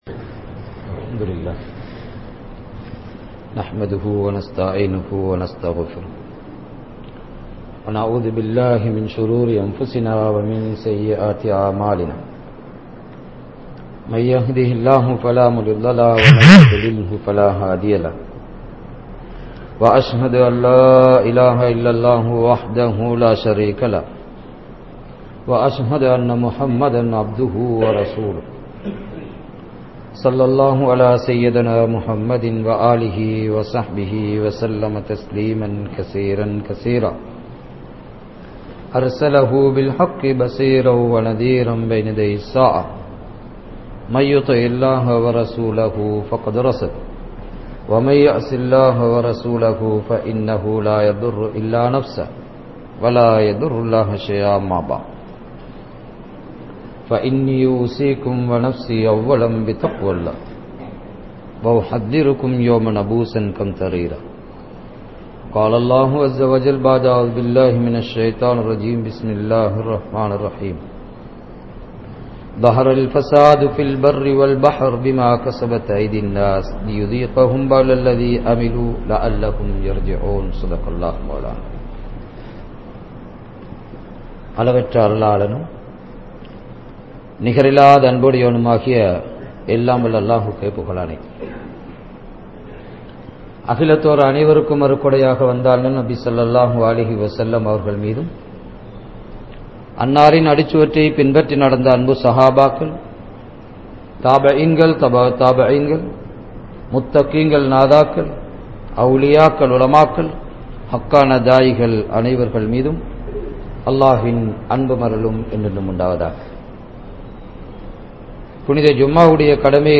Islamiya Maruththuvam (இஸ்லாமிய மருத்துவம்) | Audio Bayans | All Ceylon Muslim Youth Community | Addalaichenai
Mawanella, Danagama, Masjidhul Hakam Jumua Masjidh